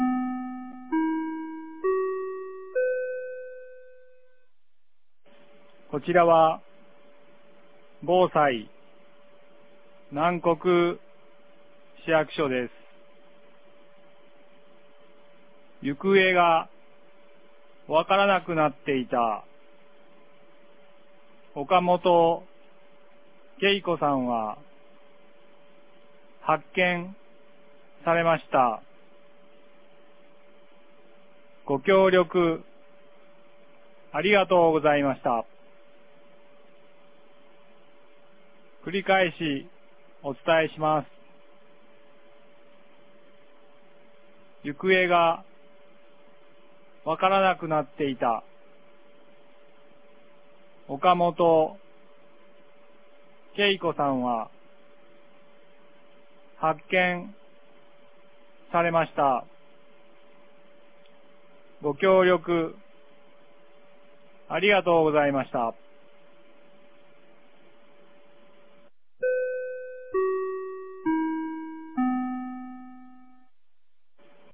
2026年04月01日 14時21分に、南国市より放送がありました。